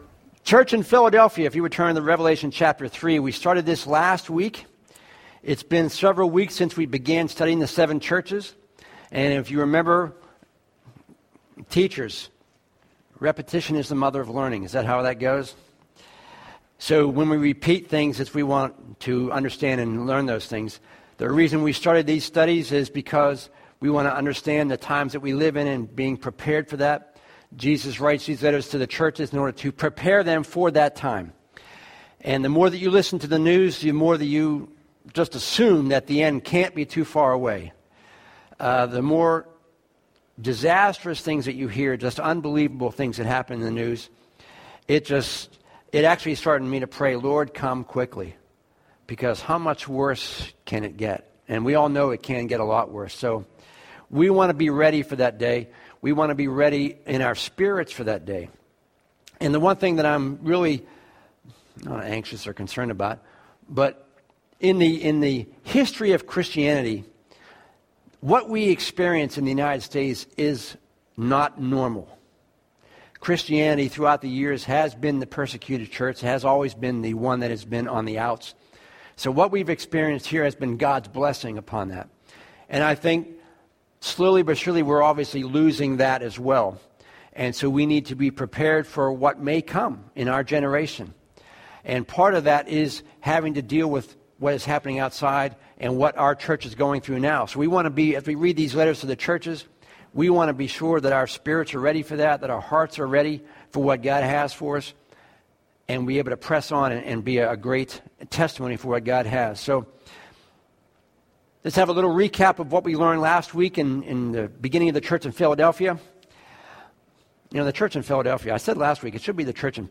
This week's sermon reminded us that we can hold onto God, as our pillar when we're going through hardship and trials.